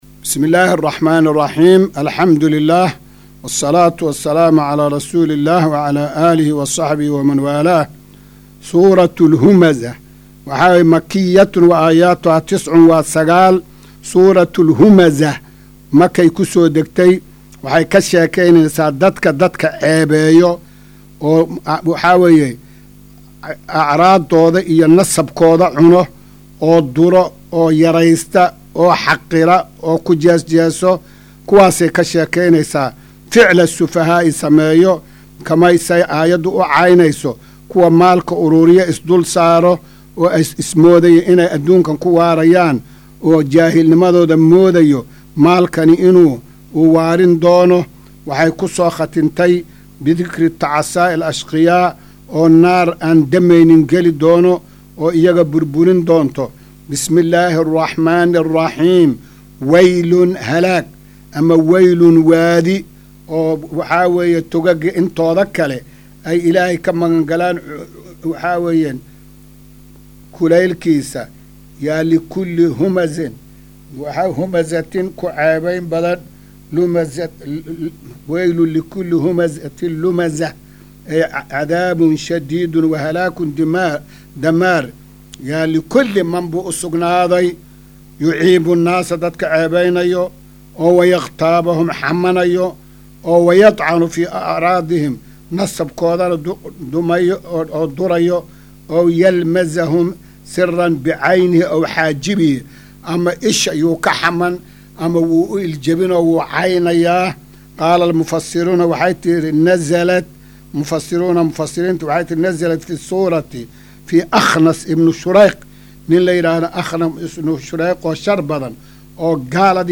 Maqal:- Casharka Tafsiirka Qur’aanka Idaacadda Himilo “Darsiga 291aad”